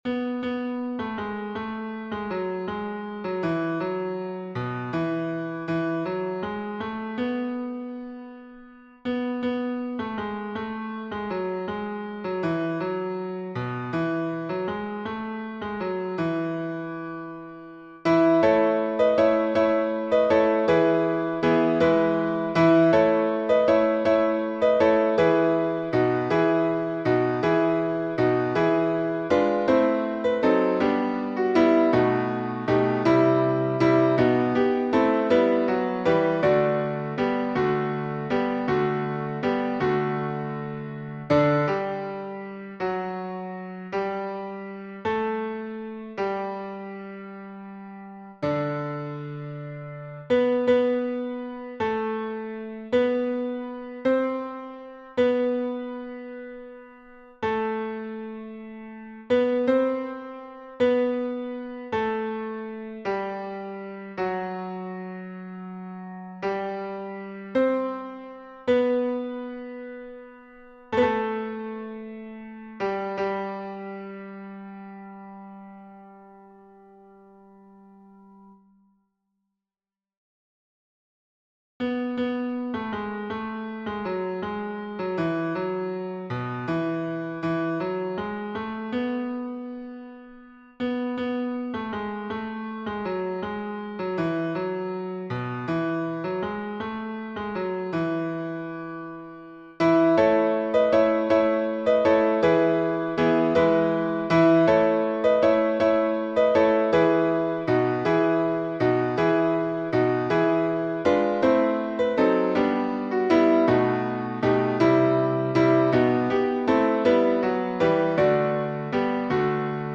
The Infant Jesus (Jesu Redemptor) Baritone or Alto solo and chorus of mixed voices Words by Fredrick H. Martens
Key signature: E major (4 sharps) Time signature: 12/8